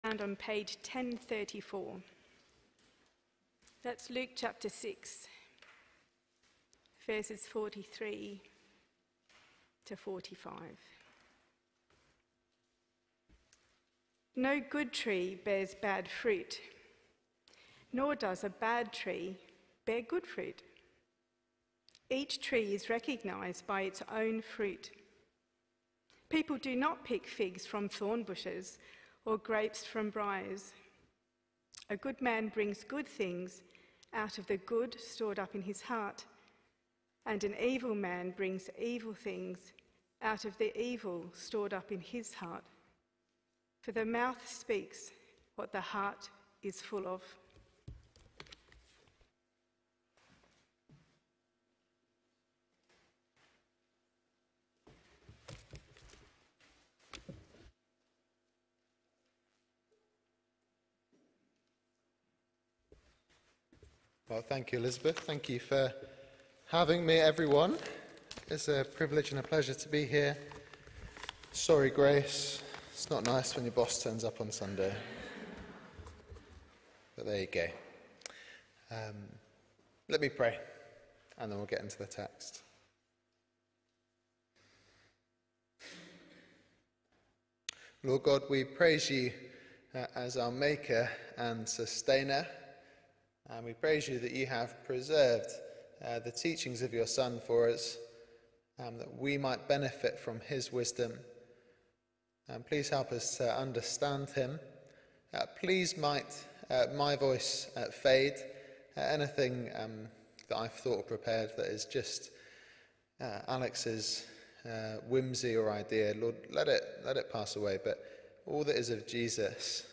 Luke 643-45 – PM Service – 9th February 2025